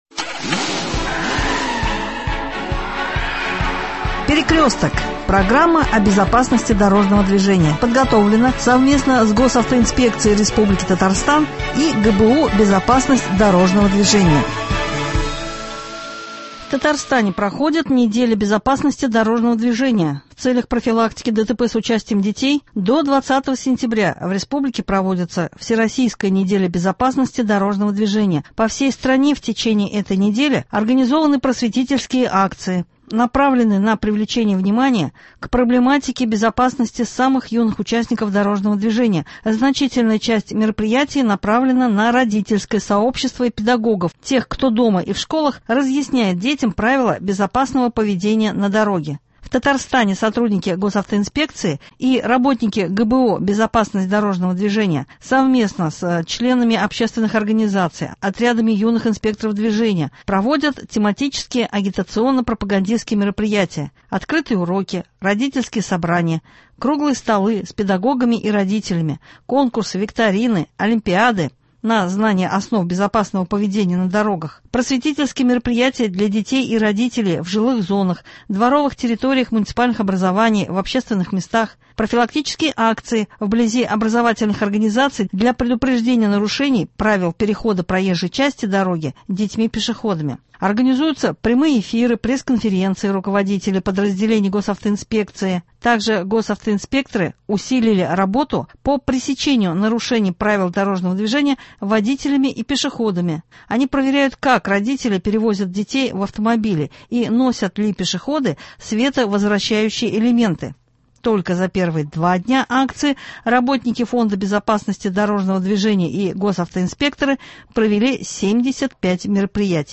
в студии ГТРК Татарстана